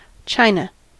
Ääntäminen
US : IPA : [ˈtʃaɪ.nə]